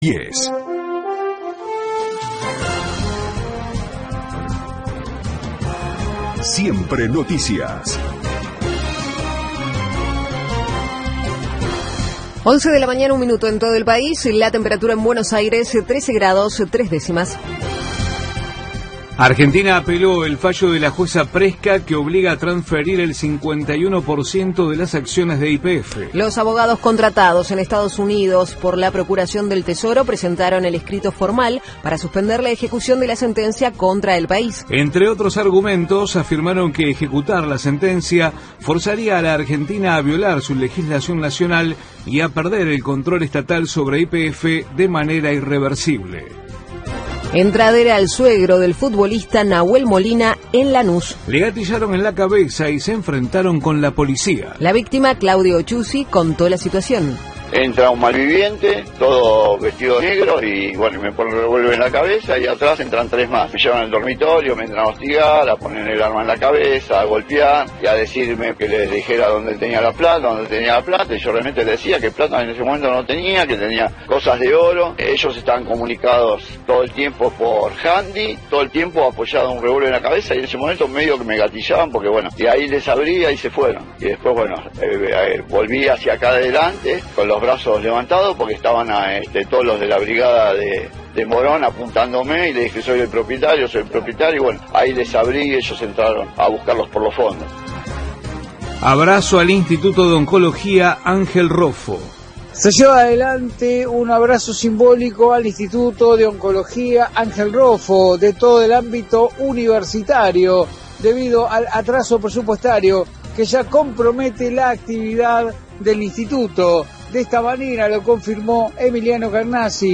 -Radio 10. Servicio informativo. 10/07/2025
Entrevista